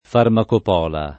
[ farmakop 0 la ]